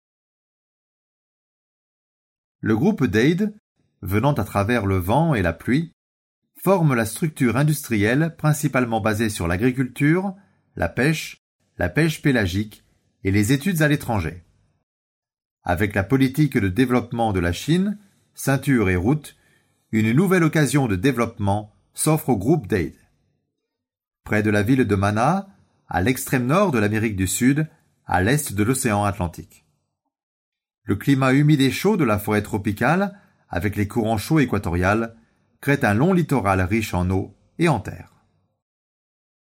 配音风格： 自然 大气 稳重 讲述